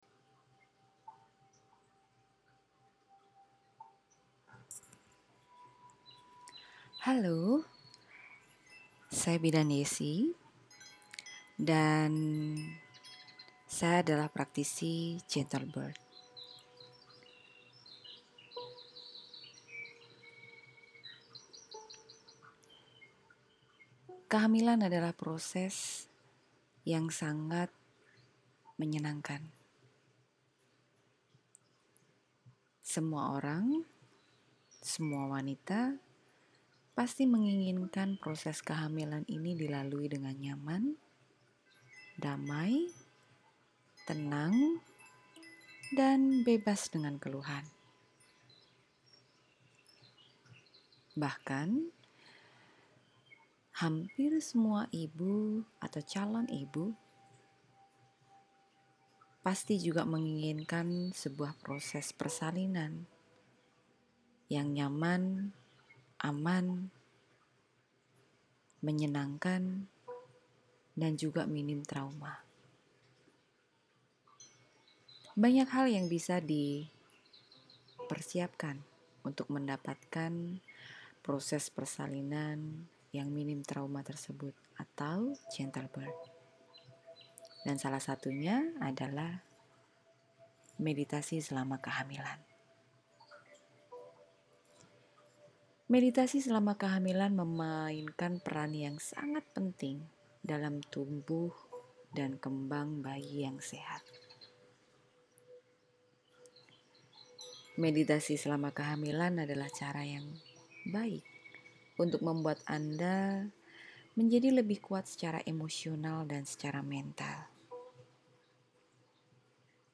Tiap hari, saya akan memandu Anda dengan panduan (audio) meditasi selama sekitar 10 menit.
Intro-ajakan-bermeditasi.mp3